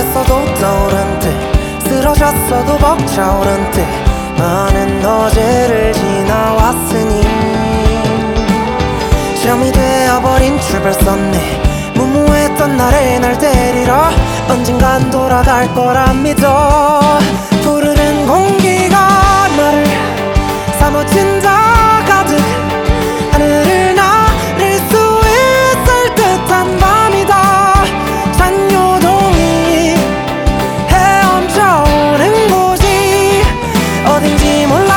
Жанр: Поп / Рок / K-pop